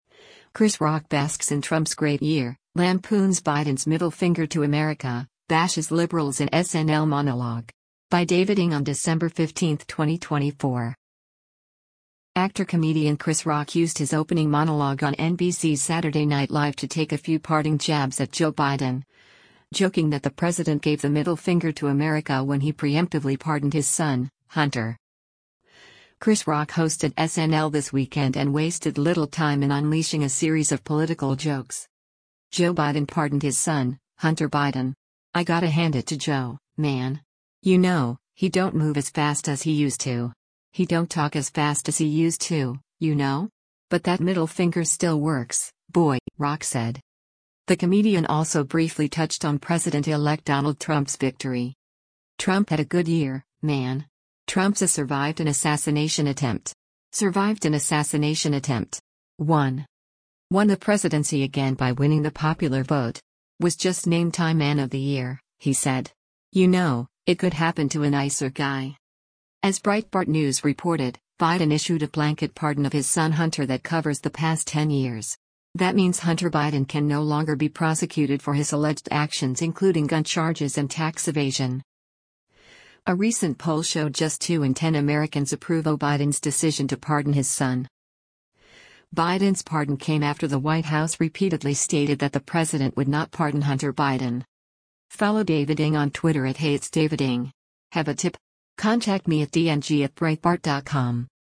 Actor-comedian Chris Rock used his opening monologue on NBC’s Saturday Night Live to take a few parting jabs at Joe Biden, joking that the president gave the “middle finger” to America when he preemptively pardoned his son, Hunter.